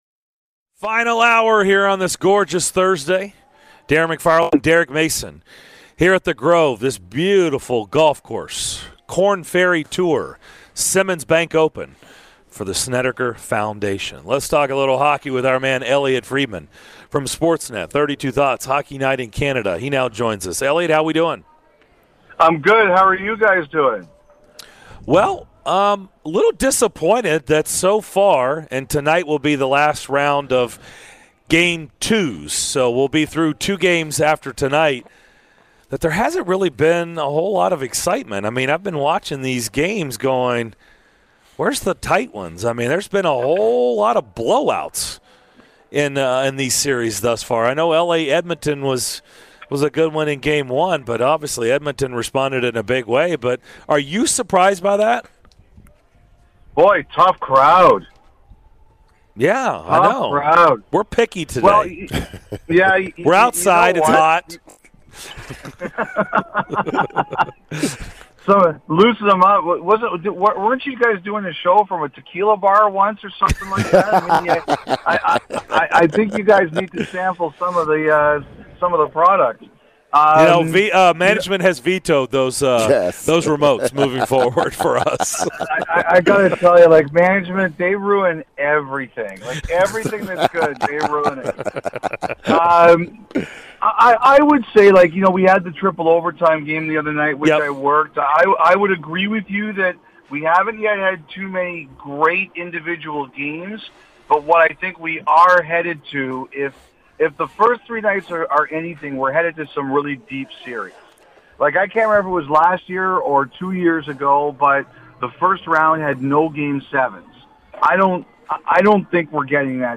Sportsnet Elliotte Freidman has his weekly visit and the DDC hears an interview with Preds forward Ryan Johansen. What minor league baseball team has the best name?